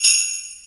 9JINGLES.wav